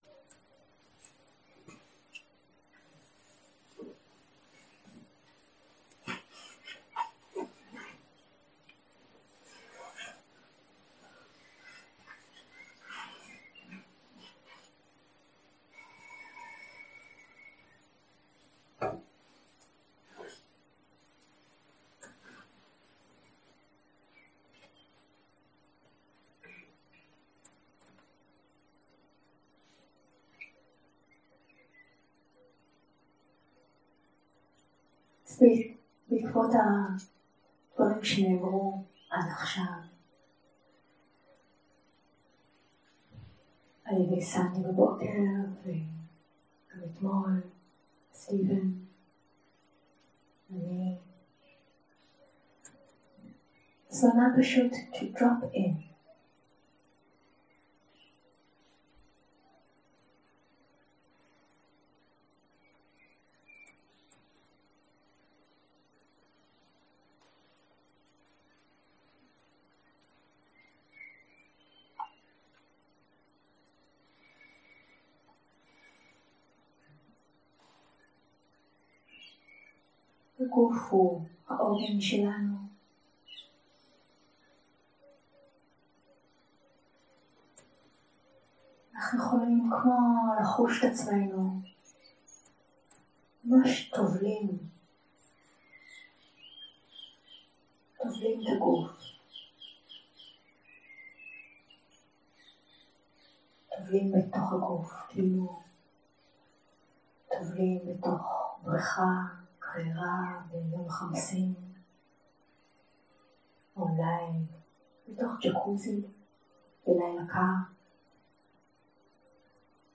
יום 2 - הקלטה 4 - צהרים - מדיטציה מונחית
סוג ההקלטה: מדיטציה מונחית
איכות ההקלטה: איכות נמוכה